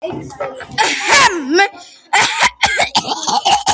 Me Choking To Death Sound Button - Free Download & Play
Pranks Soundboard0 views